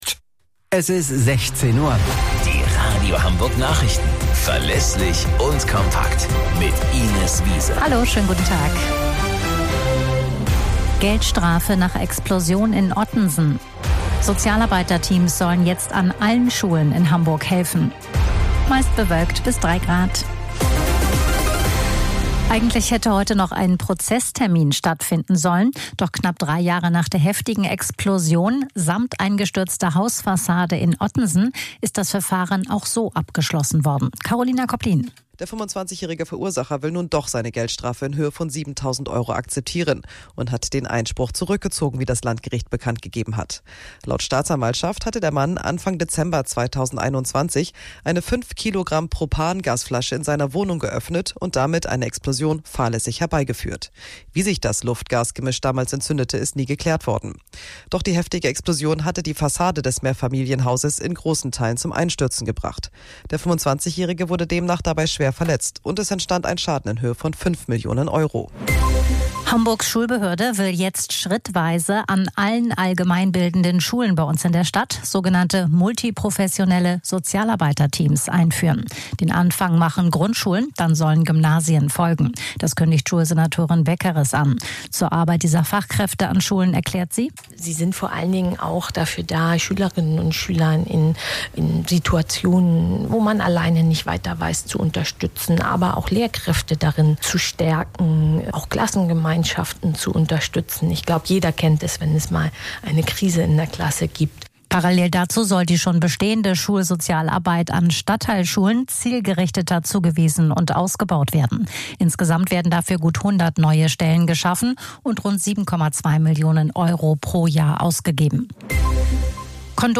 Radio Hamburg Nachrichten vom 21.01.2025 um 17 Uhr - 21.01.2025